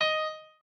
piano11_8.ogg